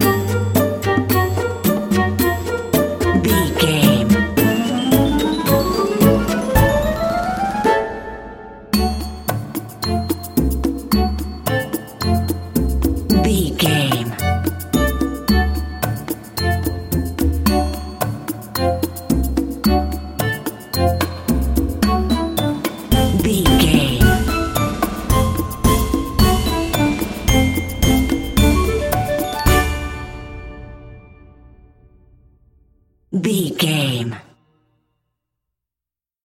Ionian/Major
orchestra
flutes
percussion
conga
oboe
strings
silly
circus
goofy
comical
cheerful
perky
Light hearted
quirky